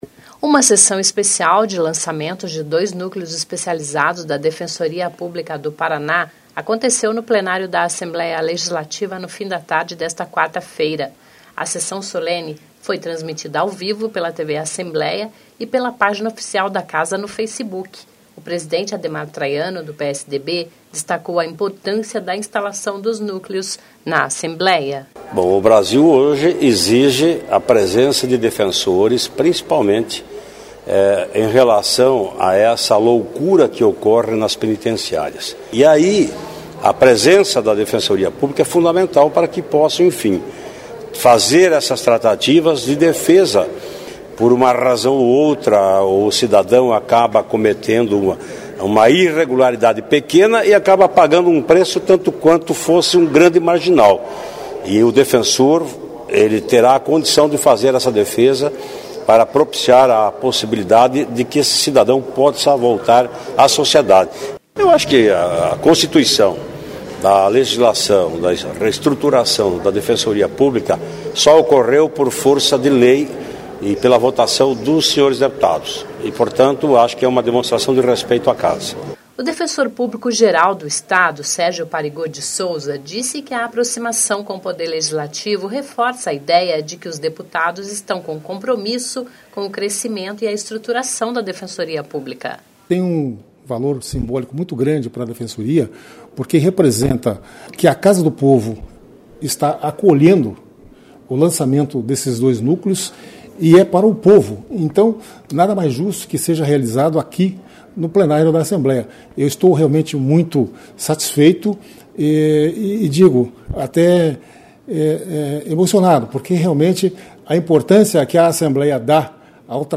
08/02/2017 - Entrevista com o presidente de Alep, Ademar Traiano, sobre o lançamento dos núcleos especializados da DPPR